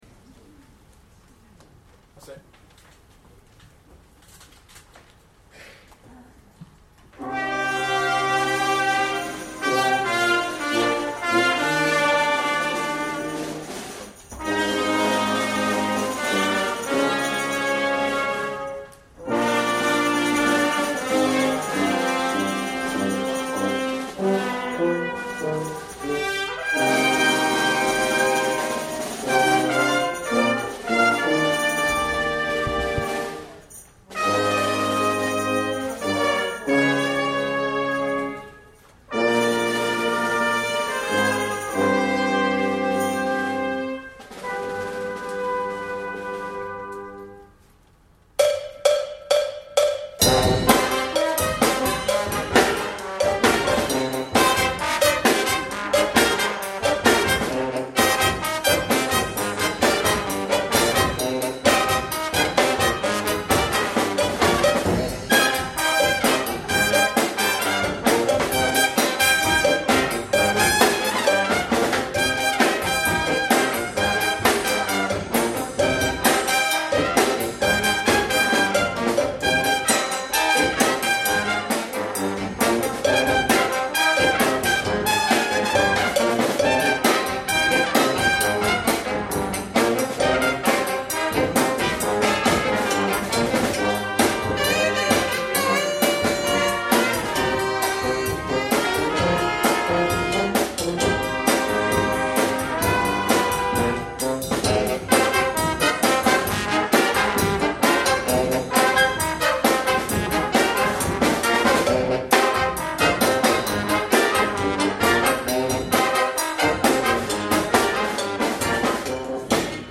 Summer Concert 2014